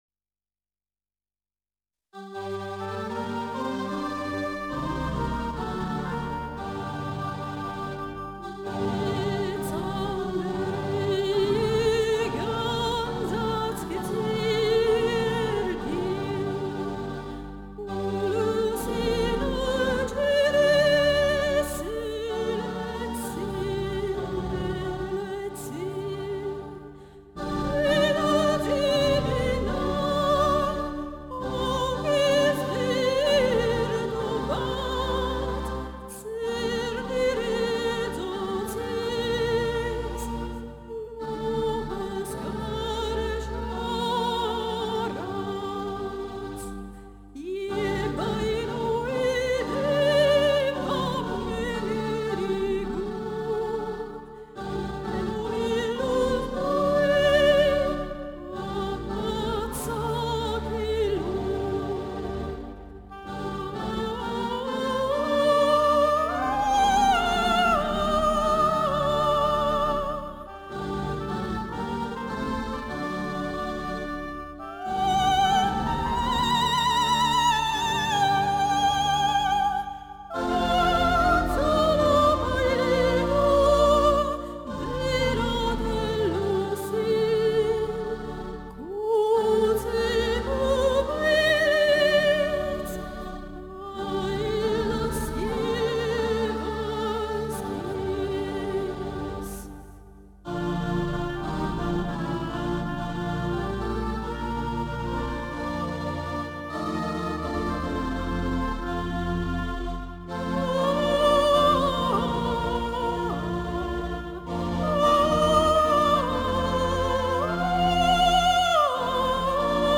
Diplomata come soprano lirico di coloritura, oltre al repertorio tradizionale operistico soprattutto verdiano e mozartiano con il quale ha svolto un’intensa attività in Italia e all’estero (Giappone, Francia, Russia), ha partecipato a molti galà di operetta e musical.
Canto armeno